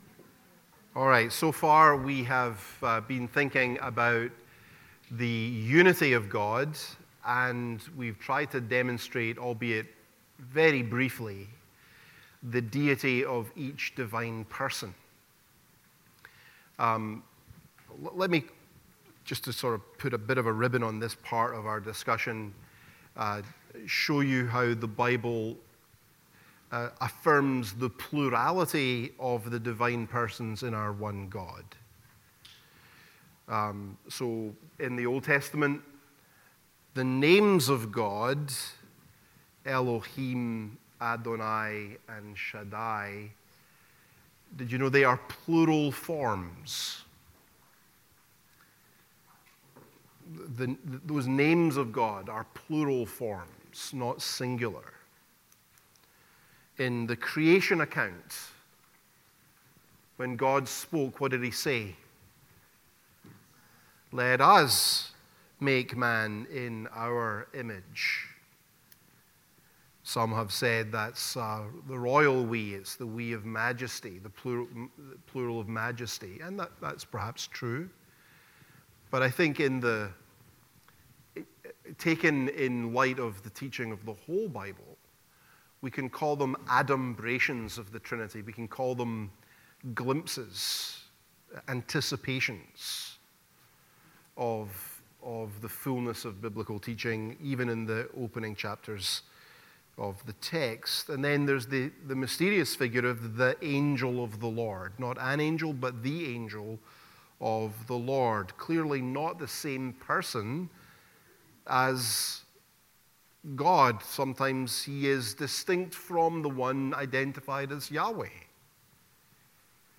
The-Doctrine-of-the-Trinity-Lecture-2-Scriptural-Testimony-to-the-Trinity-and-Trinitarian-Heresies.mp3